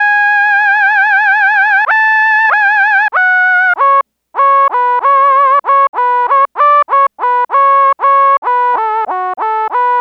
Track 14 - Lead Synth.wav